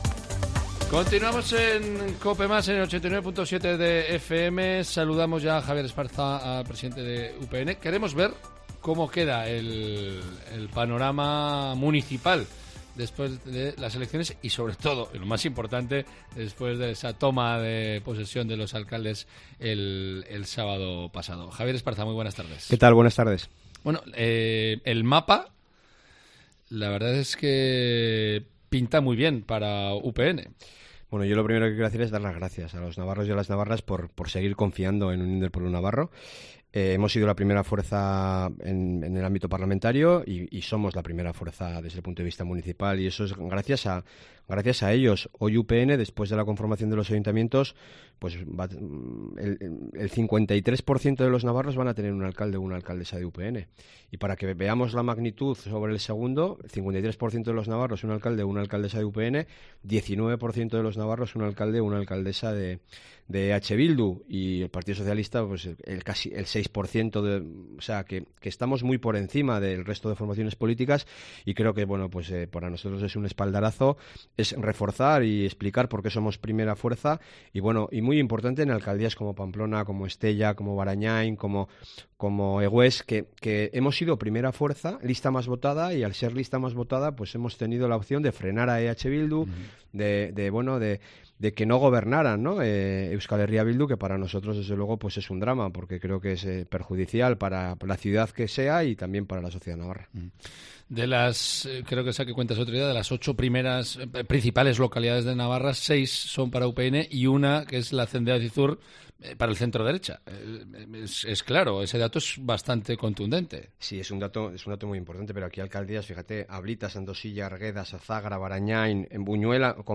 El presidente de UPN y candidato a presidir el Gobierno de Navarra por la formación regionalista, Javier Esparza, ha pasado por los micrófonos de Cope Navarra para analizar cómo ha quedado el mapa local después de que el sábado 17 de junio se conformaran todos los ayuntamientos.